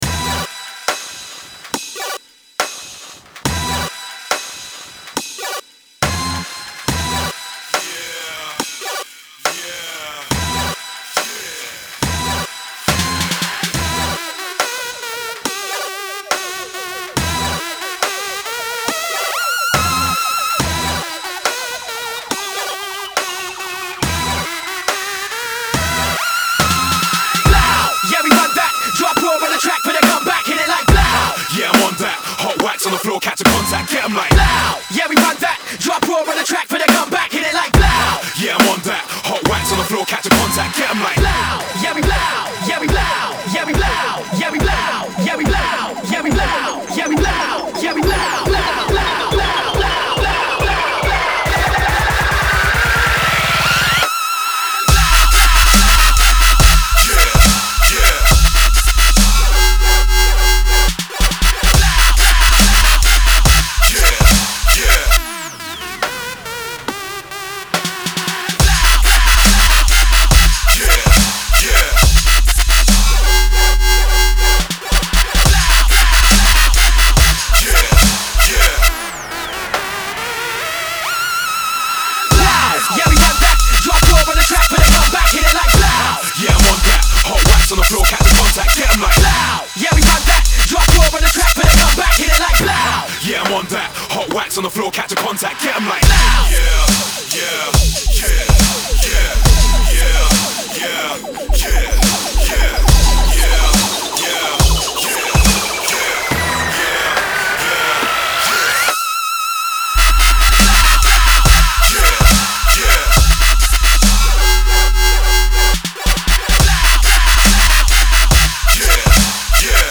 dubstep